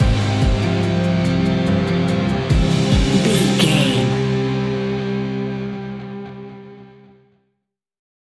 Fast paced
In-crescendo
Ionian/Major
C♭
industrial
dark ambient
EBM
drone
synths